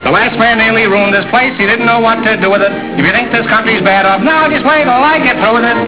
One of Groucho Marx's clever quips from " Duck Soup."